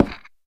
creaking_heart_place2.ogg